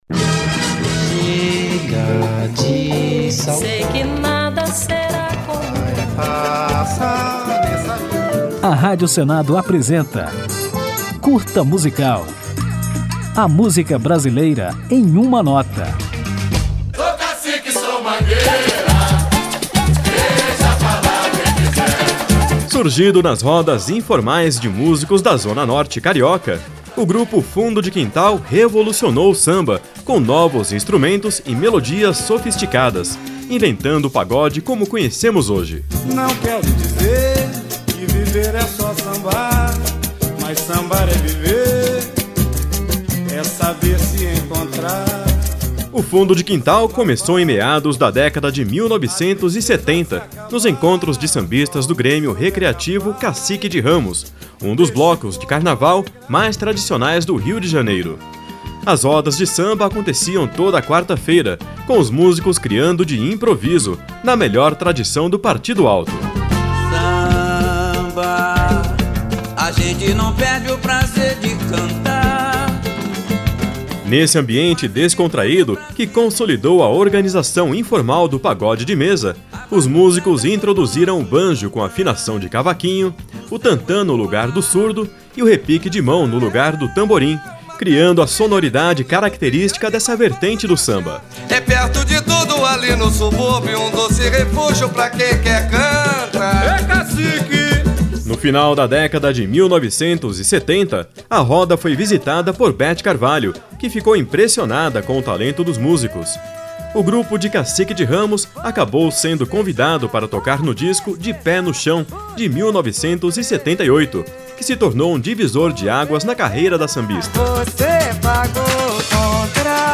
Surgido nas rodas do Bloco Recreativo Cacique de Ramos, na zona norte do Rio de Janeiro, o Fundo de Quintal revolucionou o samba com novos instrumentos e melodias sofisticadas, consolidando o pagode carioca. Nesse curta musical, vamos conhecer um pouco da história do grupo e ouvir a música O Show Tem Que Continuar, um dos maiores sucessos do Fundo de Quintal.
Samba